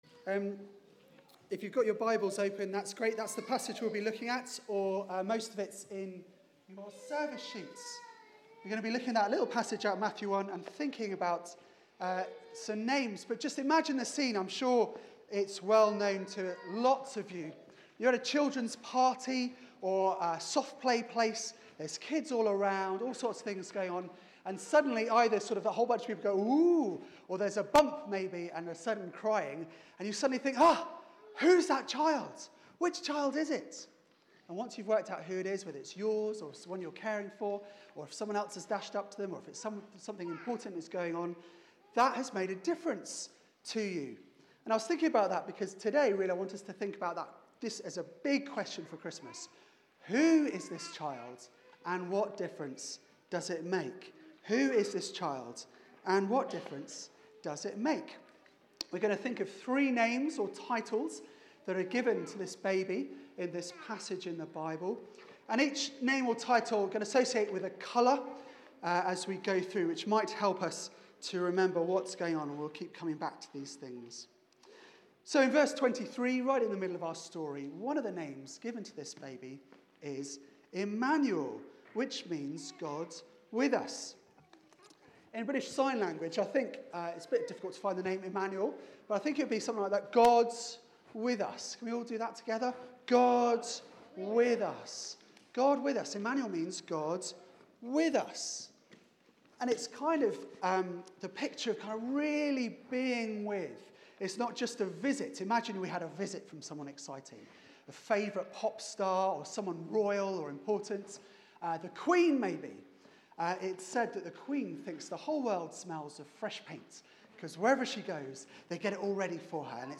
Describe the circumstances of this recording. Media for 4pm Service on Sun 18th Dec 2016 16:00 Speaker